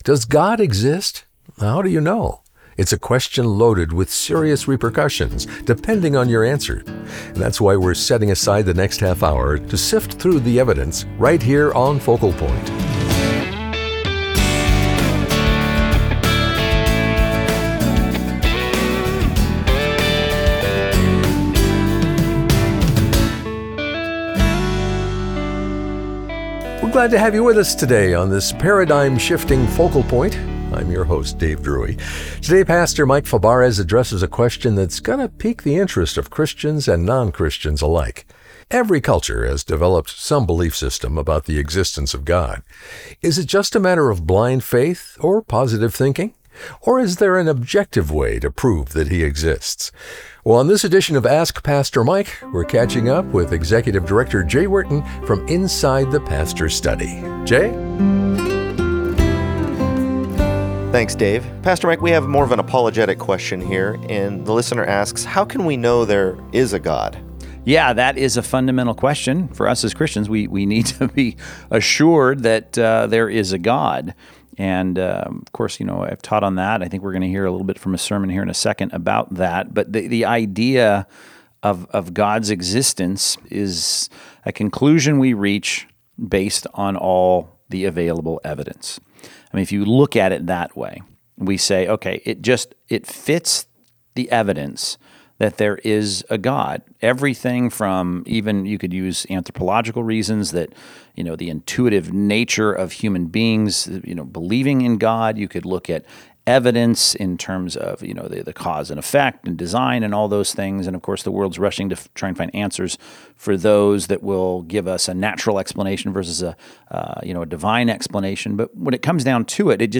This commentary skips around once you get into the sermon.